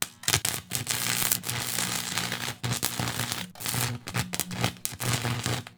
ConnectingWires.wav